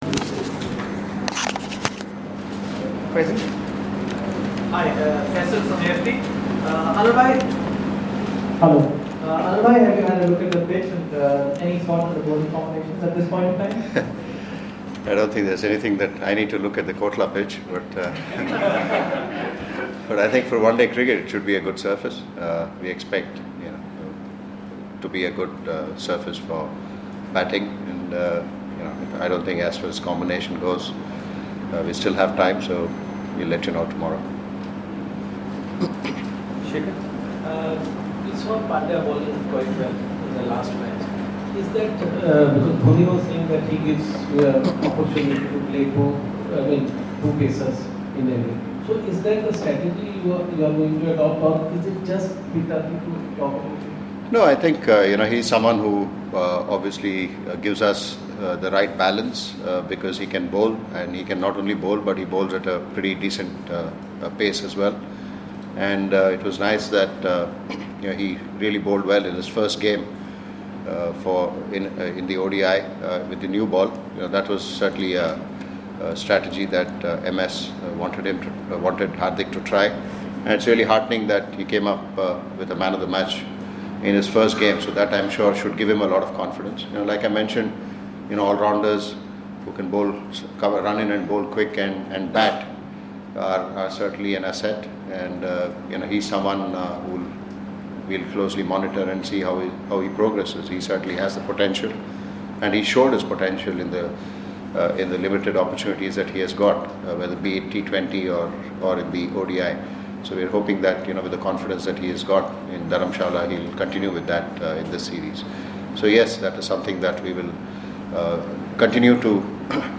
LISTEN: Anil Kumble speaking ahead of the 2nd ODI in New Delhi